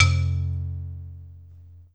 Index of /90_sSampleCDs/USB Soundscan vol.02 - Underground Hip Hop [AKAI] 1CD/Partition D/06-MISC
BALAFON 2 -R.wav